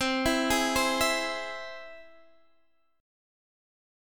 Listen to C+ strummed